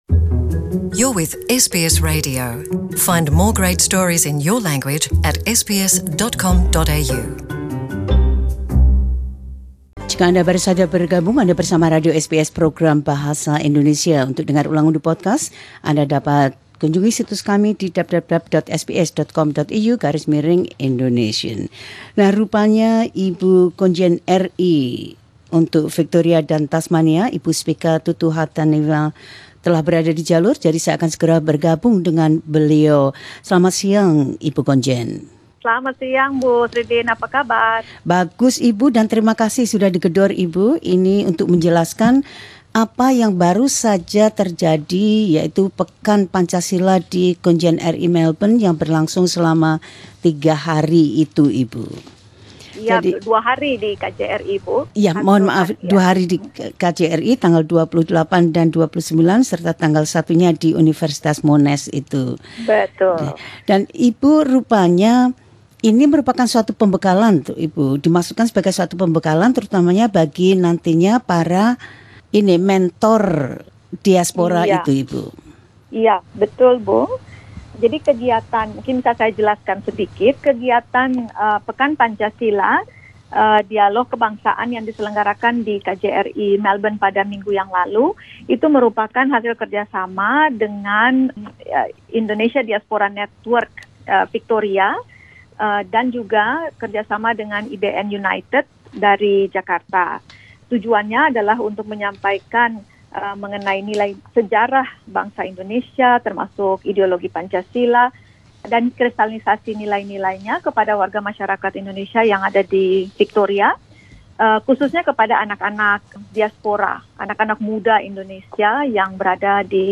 Spica A. Tutuhatunewa, Consul-General for the Republic of Indonesia in Victoria and Tasmania talks about the purpose of ‘Pekan Pancasila’, a series of workshops, dialogues and seminars, held in Melbourne, from 28 November-1 December by the Indonesian Diaspora Network Victoria.